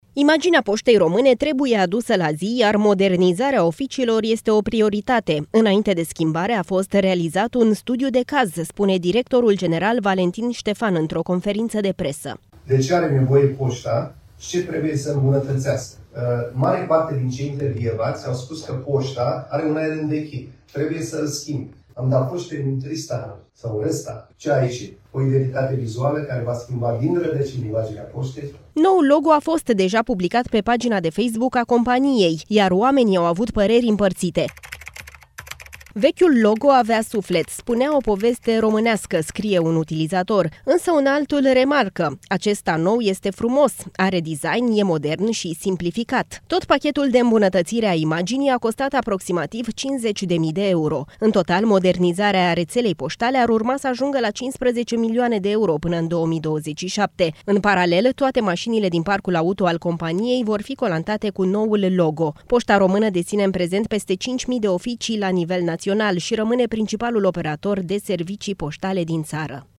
într-o conferință de presă.